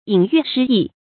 引喻失义 yǐn yù shī yì 成语解释 引喻：引用类似的例证来说明事理。